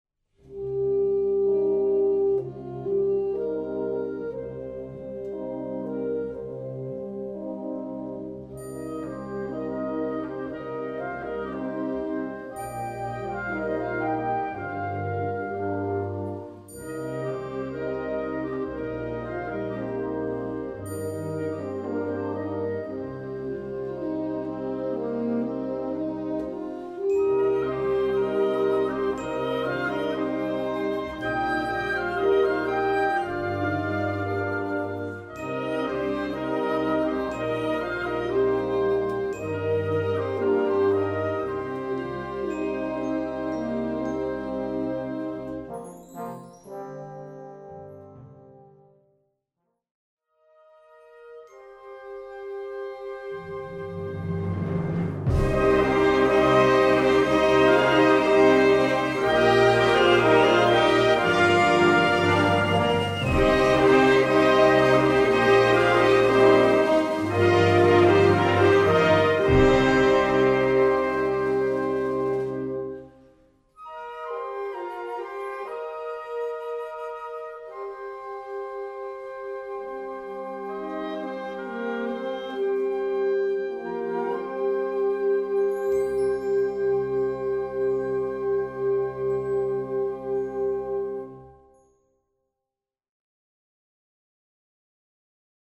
Besetzung: Blasorchester